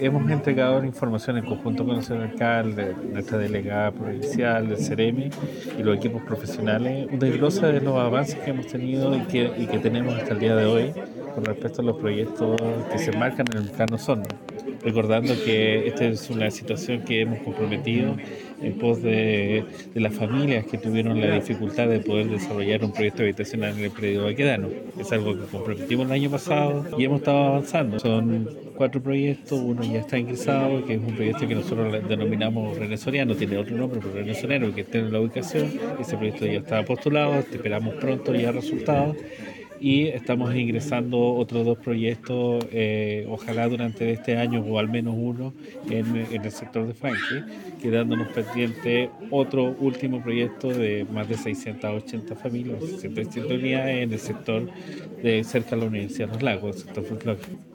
Por su parte el Director Regional del Servicio de Vivienda y Urbanismo, Álvaro Valenzuela, señaló que en el Plan Osorno se han dado avances para  proyectos habitacionales en distintos sectores de la comuna, que incluyen las cercanías a la Universidad de Los Lagos, Francke, Sector Oriente.